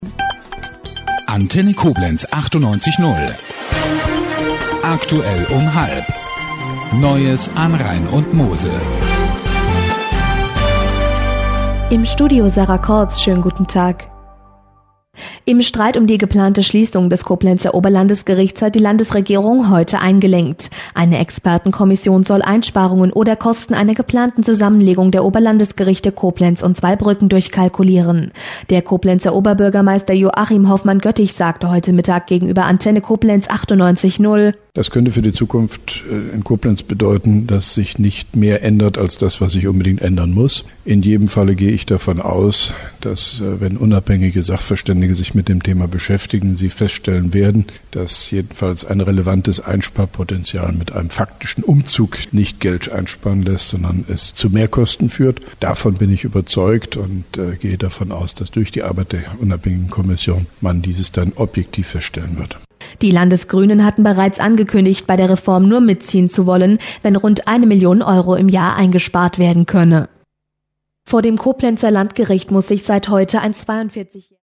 Mit Kurzstellungnahme von OB Hofmann-Göttig